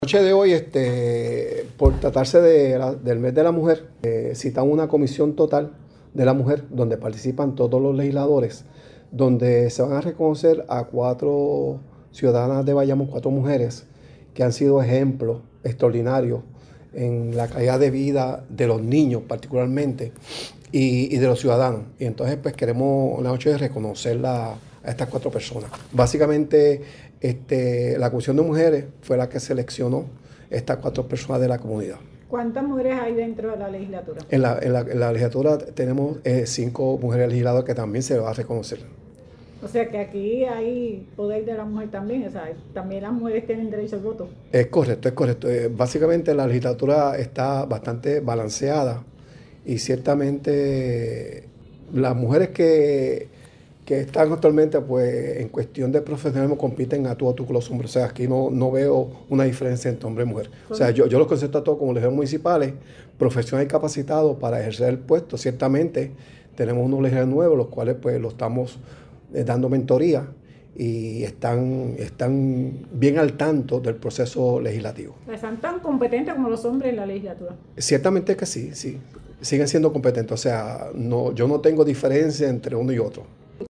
En el marco de la Semana de la Mujer la Legislatura Municipal de Bayamón rindió homenaje a cuatro mujeres cuya trayectoria refleja compromiso, liderazgo y vocación de servicio - Foro Noticioso Puerto Rico
PRESIDENTE-LEGISLATURA-BAYAMON.mp3